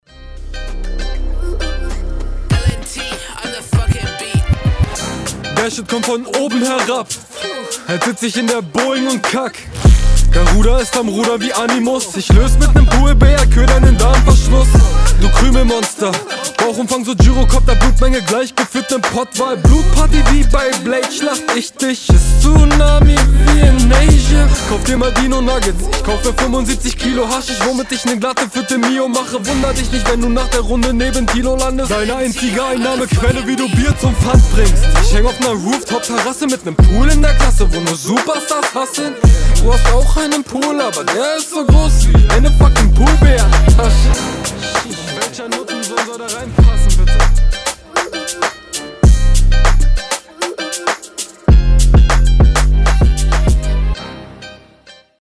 Stimme geht irgendwie sehr im Beat unter, der ist bisschen zu voll für die Art …